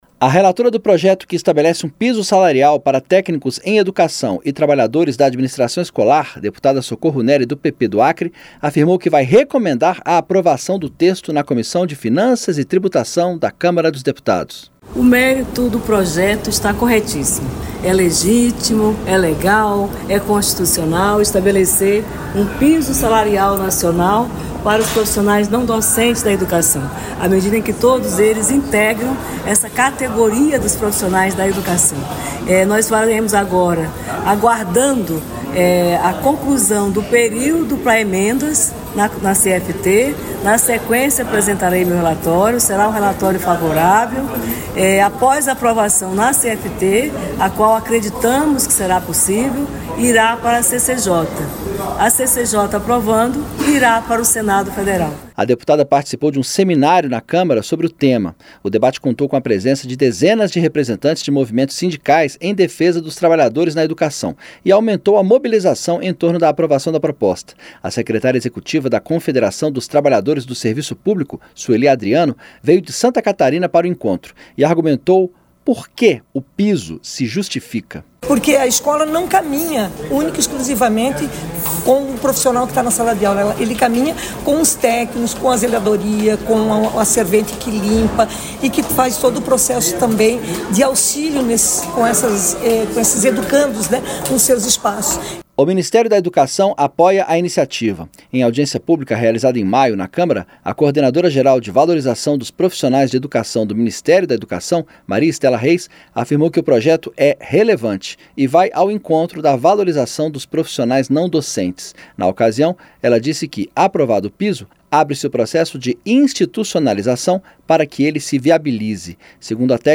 RELATORA VAI RECOMENDAR APROVAÇÃO DE PISO SALARIAL PARA TÉCNICOS E TRABALHADORES DA ADMINISTRAÇÃO ESCOLAR. CATEGORIA SE MOBILIZA PELA VOTAÇÃO NA CÂMARA, COMO DETALHA O REPÓRTER